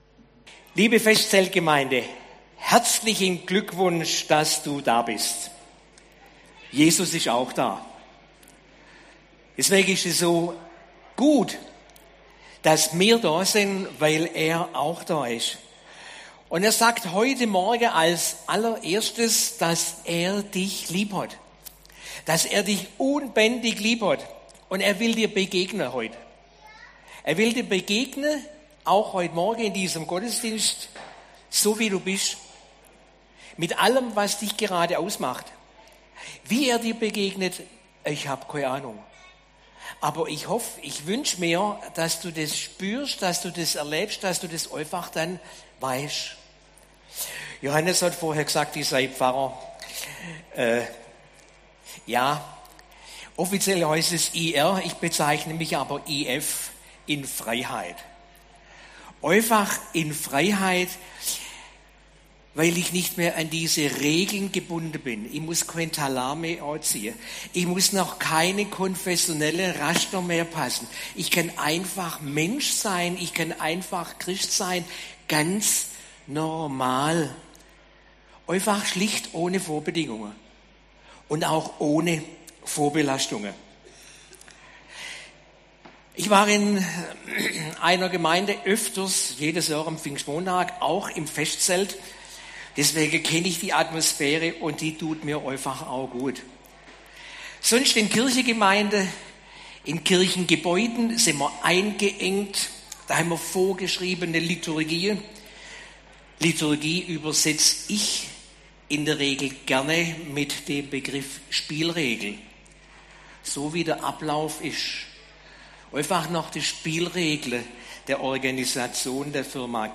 Predigt im Motorradgottesdienst im Festzelt